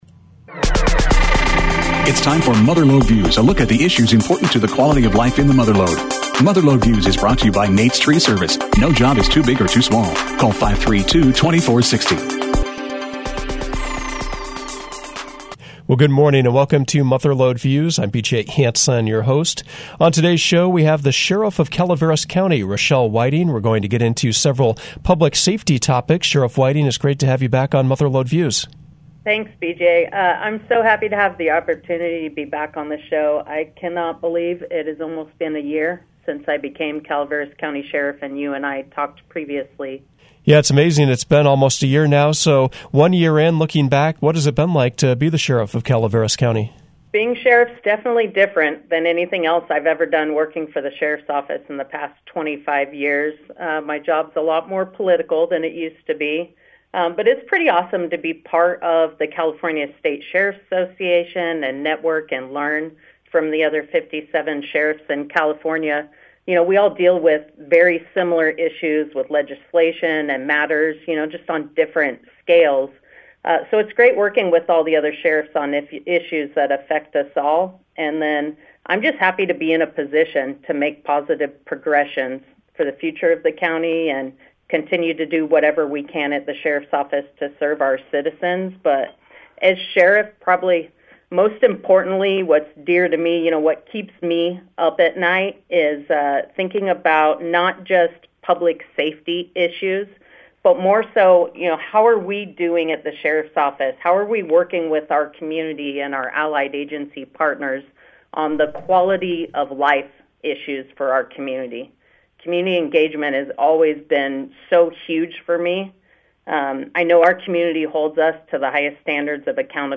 Mother Lode Views featured Calaveras County Sheriff Rachelle Whiting. She spoke about her first year in office, goals, and initiatives. She also recapped local crime trends, highlighted findings from the Cold Case Task Force, spoke about how technology is changing law enforcement, and provided important reminders for the fire season.